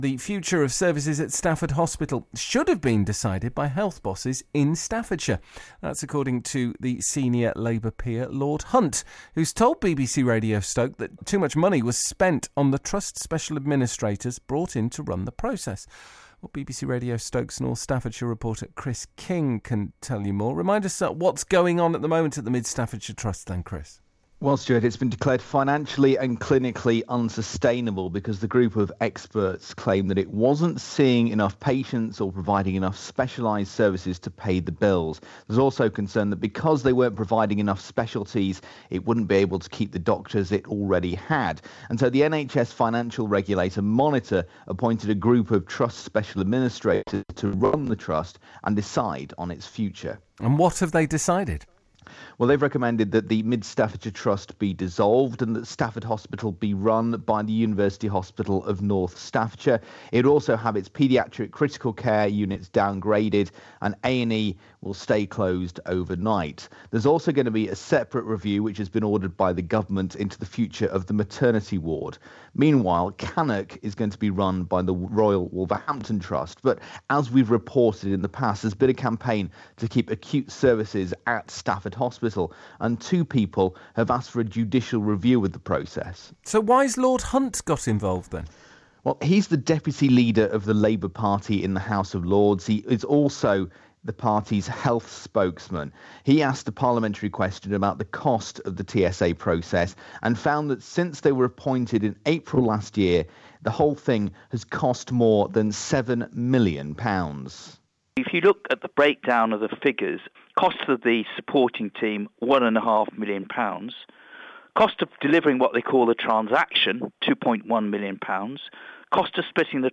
The senior Labour Peer Lord Hunt has criticed the process used to decide on the future of services at Stafford Hospital. Here's my report for BBC Radio Stoke.